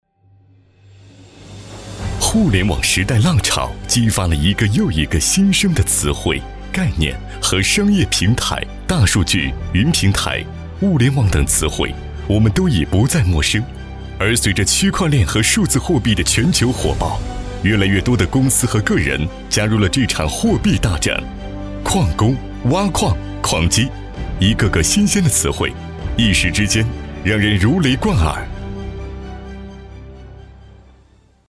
162男-大气恢宏
特点：大气浑厚 稳重磁性 激情力度 成熟厚重
风格:浑厚配音